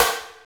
Index of /90_sSampleCDs/Roland - Rhythm Section/KIT_Drum Kits 8/KIT_Jack Swing
SNR HYPER02L.wav